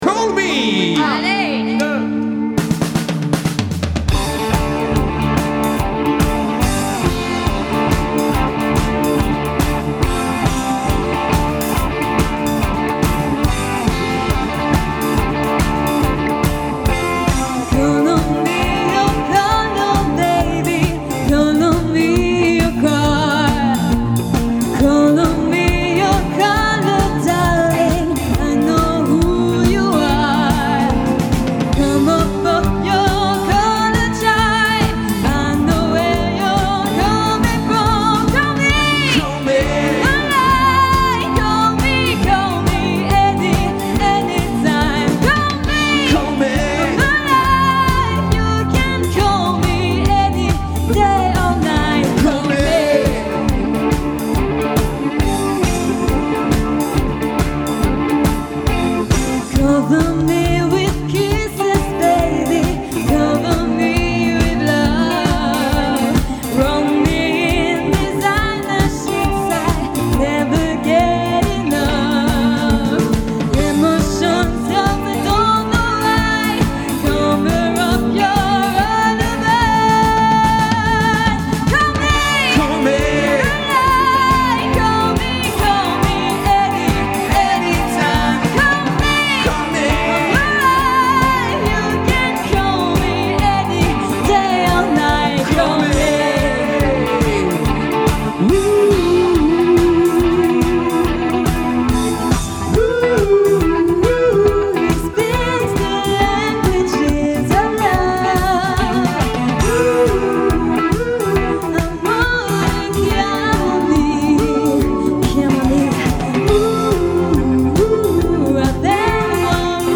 orchestre variété pop chansons années 80